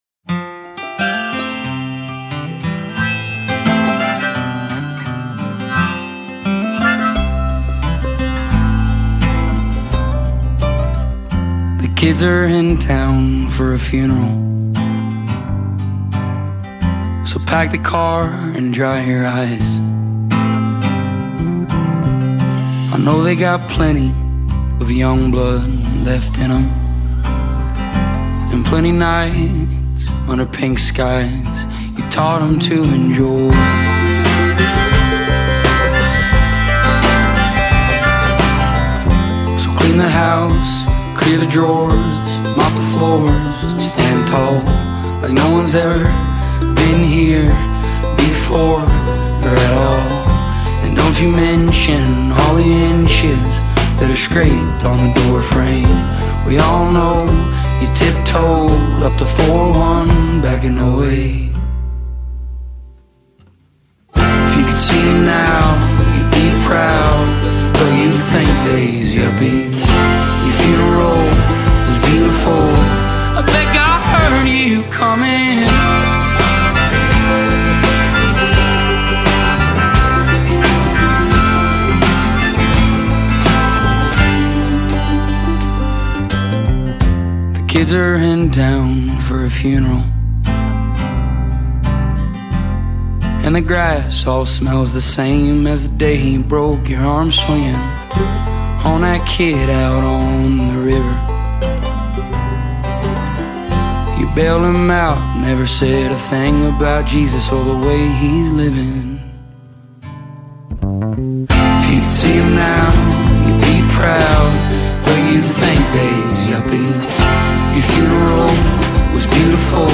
all-out emotional song